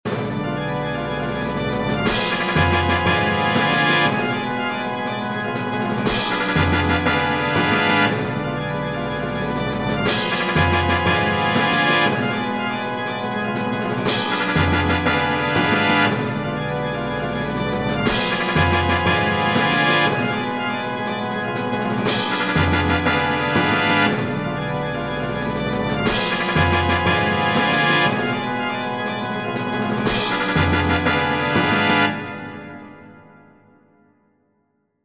Groove in 4edo
4edo_groove.mp3